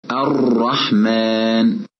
— Terminologiskt: Det lätta trillandet av tungan när man uttalar rā' på grund av dess snäva artikulationspunkt.
: Om man triller tungan mer än en gång när bokstaven uttalas, låter bokstaven som följer:
: Vi märker att den upprepning som förekommer oftast när rā’ är (mushaddad ) som i: